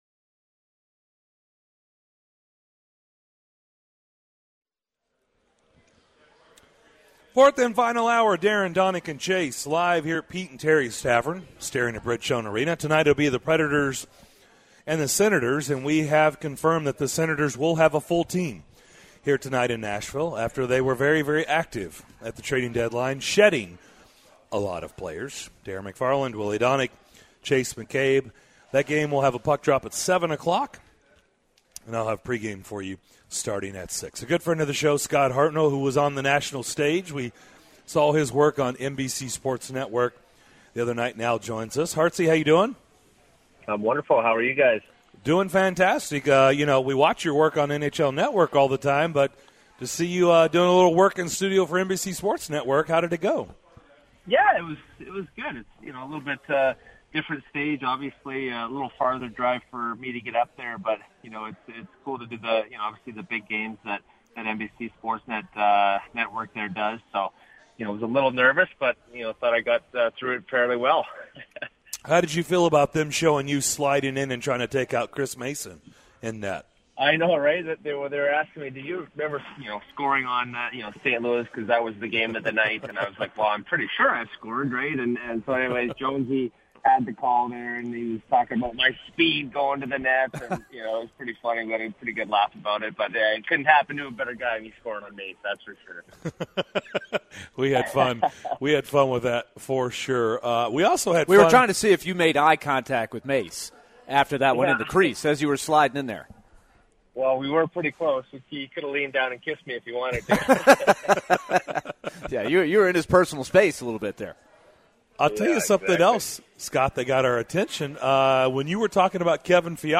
Joining DDC today former Nashville Predator and now with NHL Network Scott Hartnell. He's asked asked about the changes the Preds have done after the trade deadline.